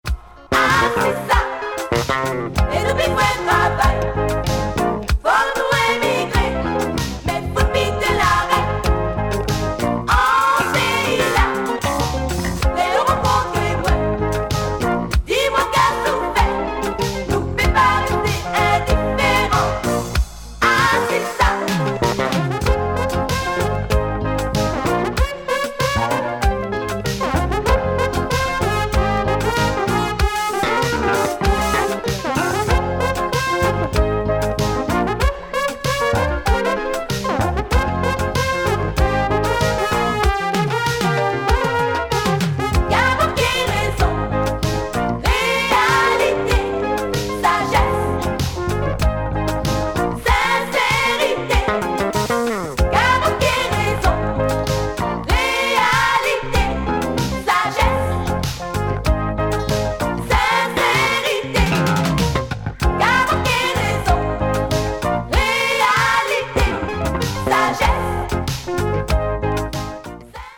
Check the slow groove
underground zouk funk
Caribbean